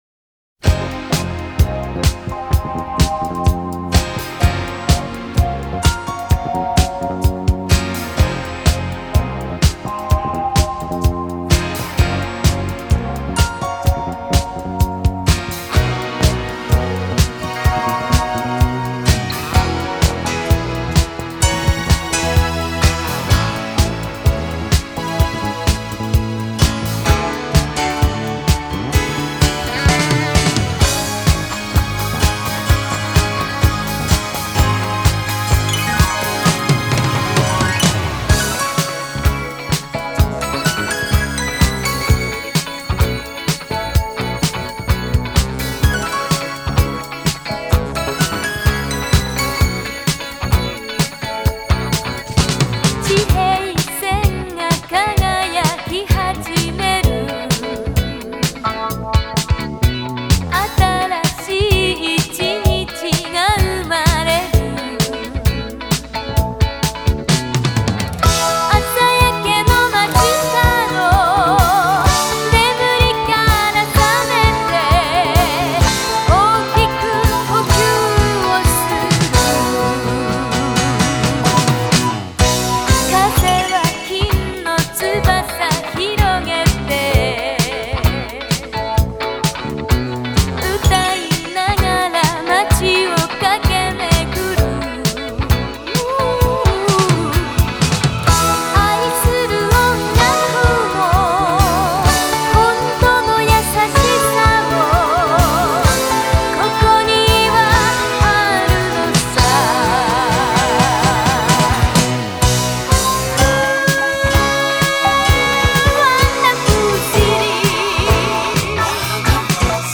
Обладает красивым и проникновенным высоким голосом.
Жанр: J-Pop, Ballad